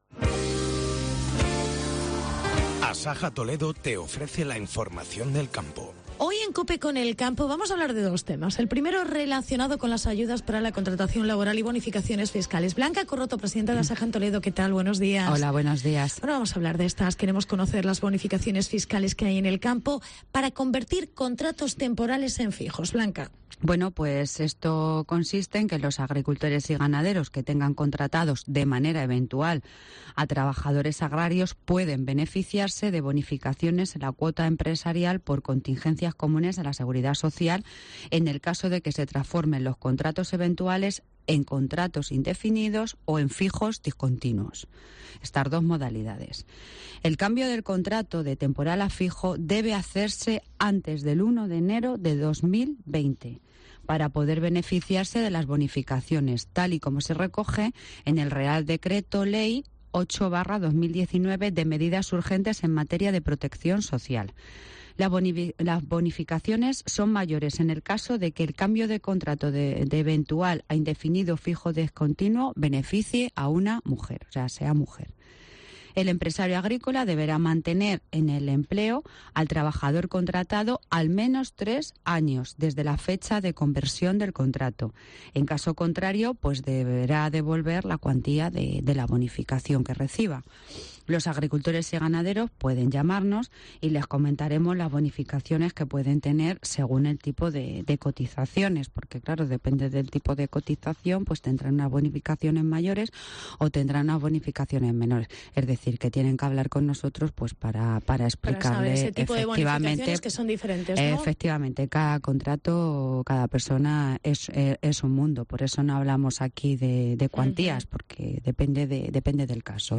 Conoce las bonificaciones por cambiar el contrato temporal a fijo en el campo. Entrevista Asaja Toledo